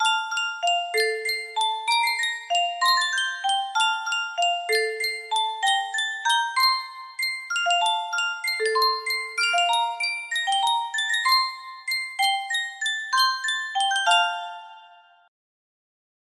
Sankyo Music Box - Unknown Tune music box melody
Full range 60